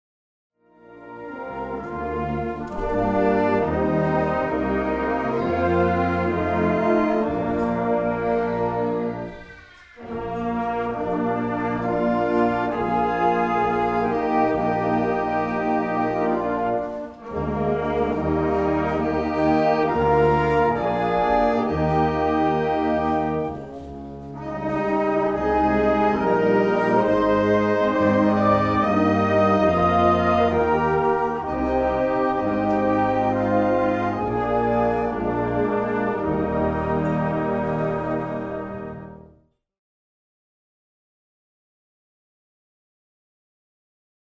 Choral